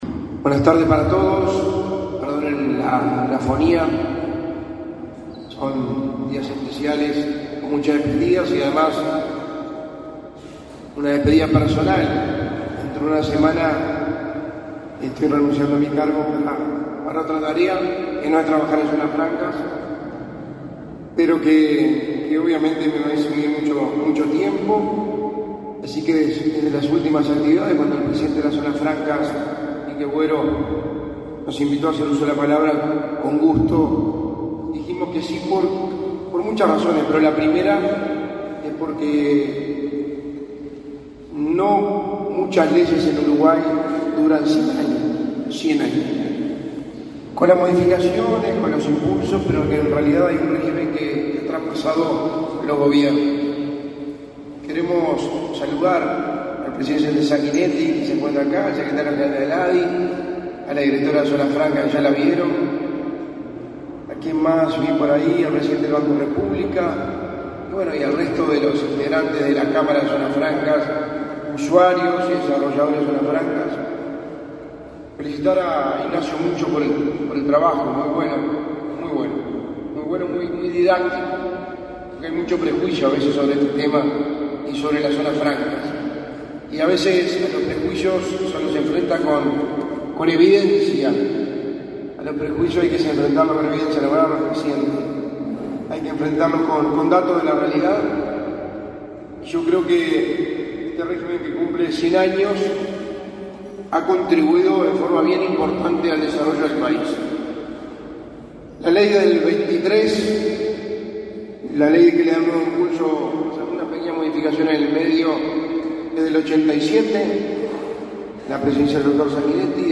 Palabras del secretario de la Presidencia, Álvaro Delgado
El secretario de la Presidencia, Álvaro Delgado, participó, este 14 de diciembre, en el acto por el centenario del régimen de zonas francas.